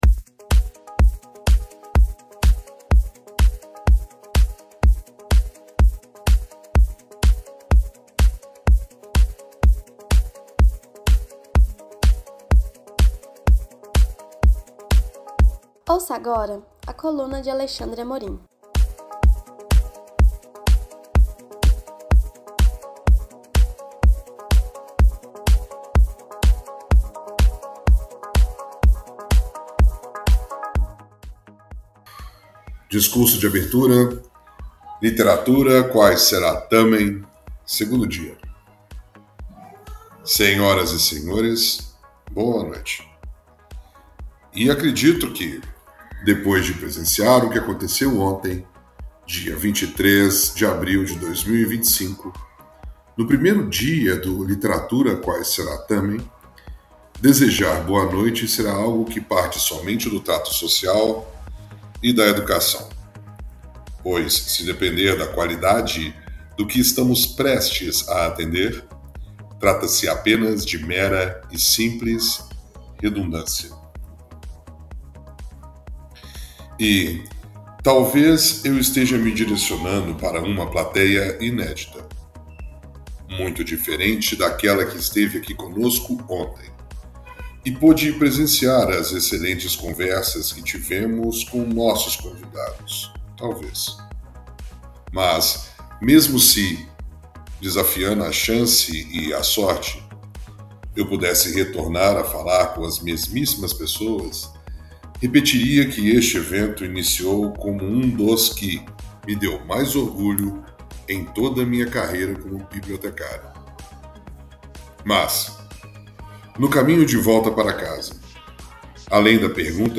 Discurso